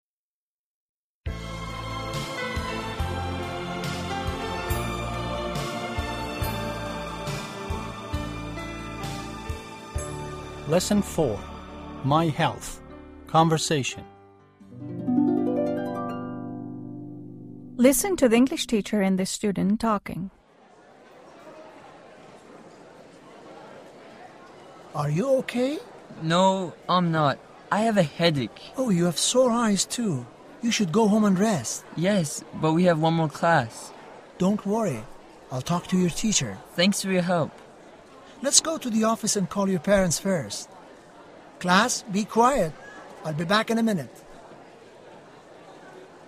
ترجمه مکالمه درس 4 انگلیسی پایه هشتم